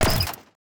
UIClick_Menu Strong Metal Rustle 04.wav